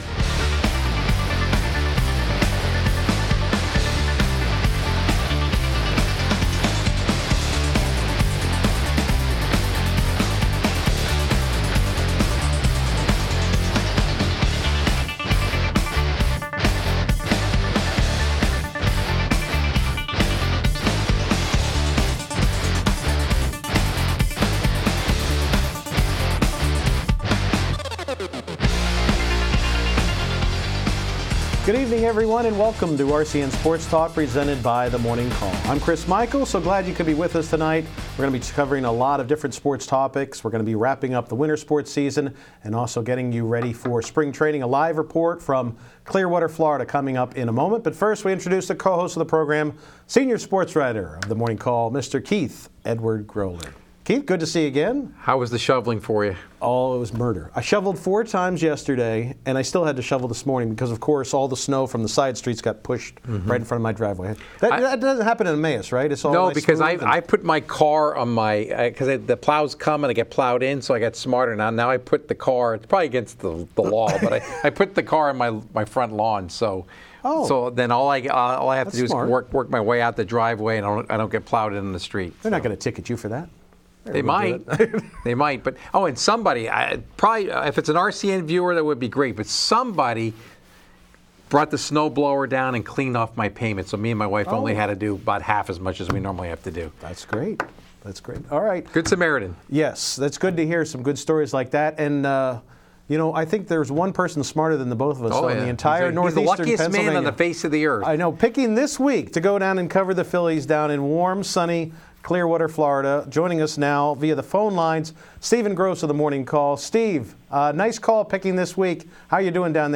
Guests include local sportswriters, coaches, athletes and announcers.